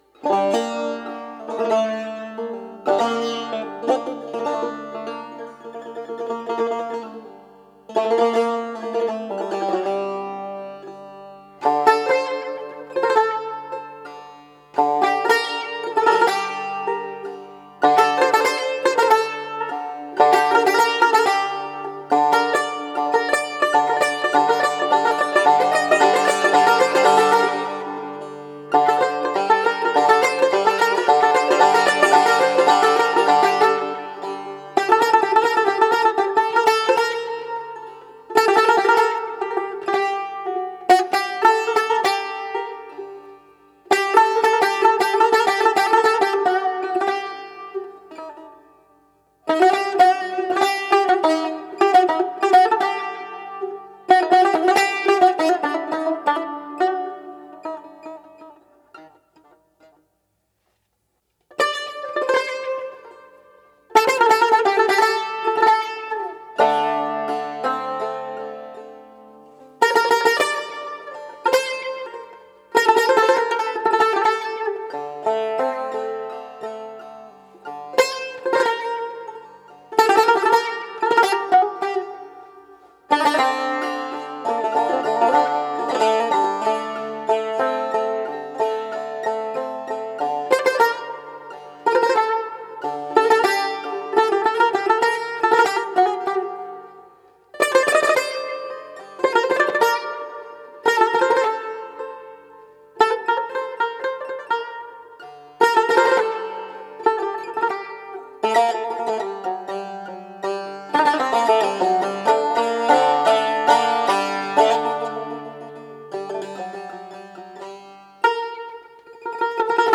2. 2 Tar (Bayat Esfahan